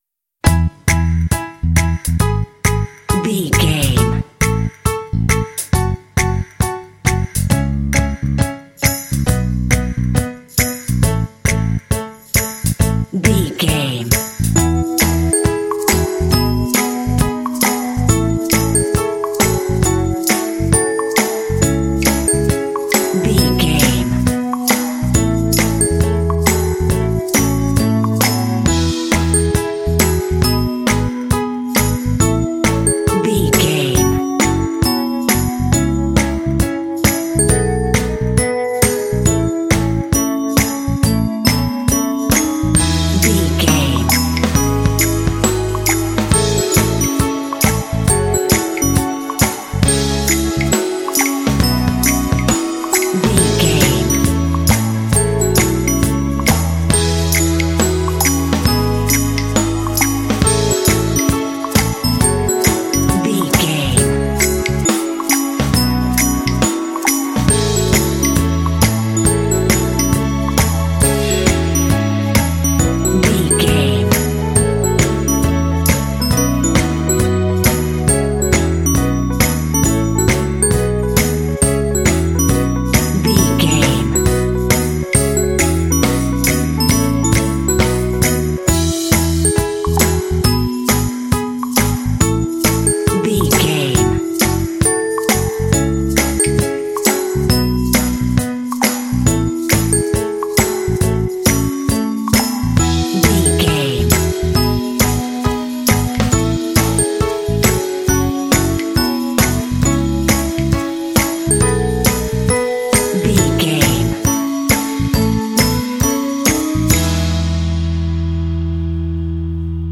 Uplifting
Ionian/Major
bouncy
groovy
bright
piano
bass guitar
drums
strings
pop
contemporary underscore